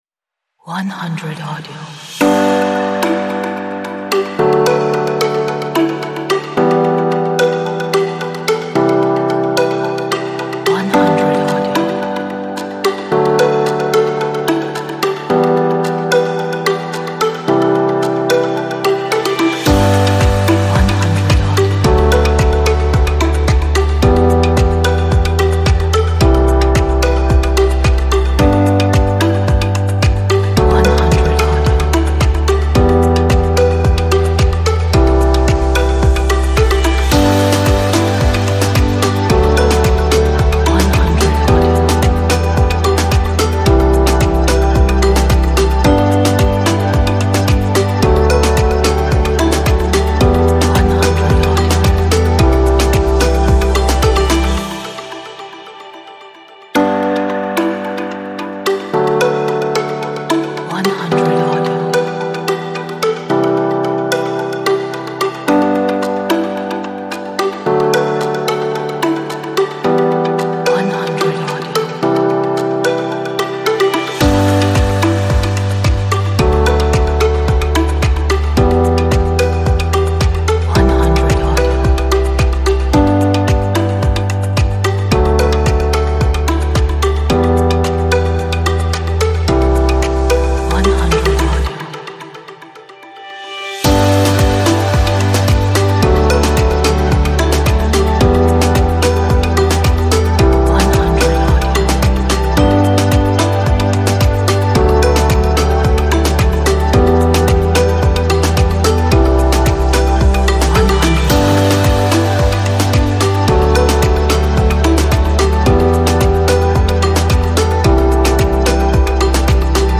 鼓舞人心的背景音乐，充满活力和快乐的心情。